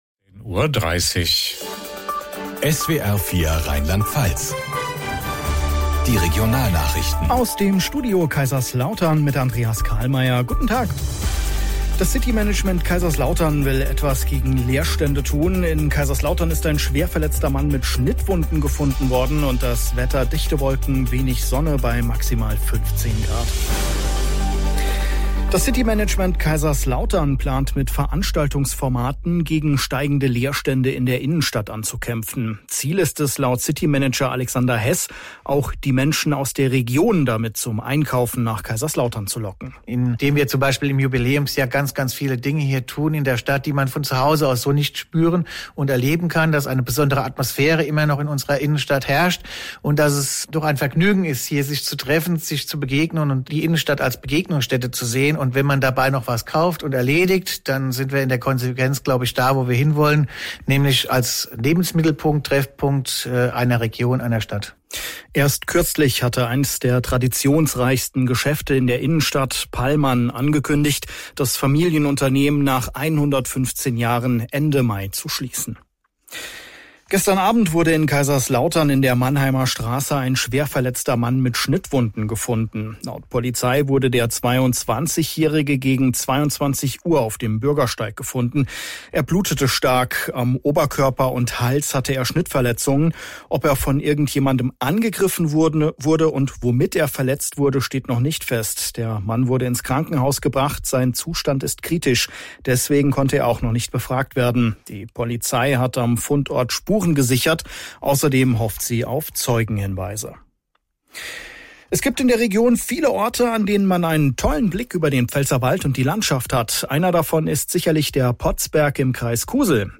SWR Regionalnachrichten aus Kaiserslautern - 15:30 Uhr